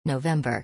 • November یازدهمین ماه سال میلادی و به شکل /noʊˈvembə/ تلفظ می‌شود.